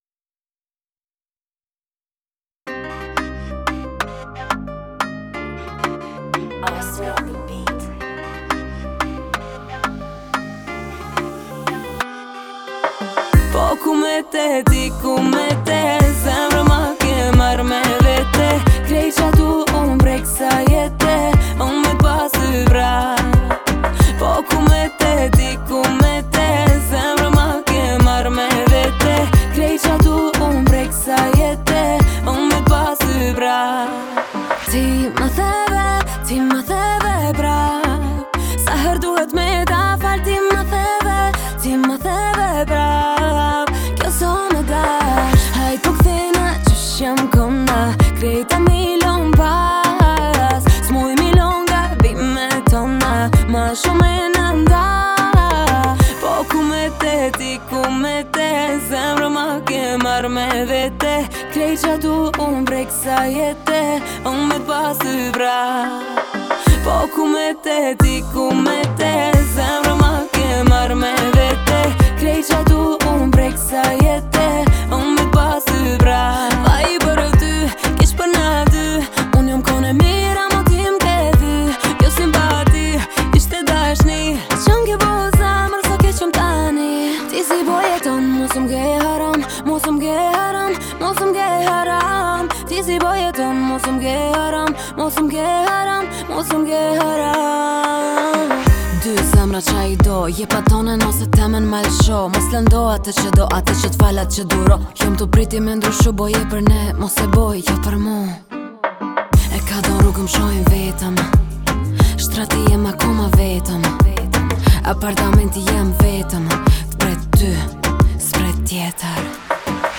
зажигательный трек албанской певицы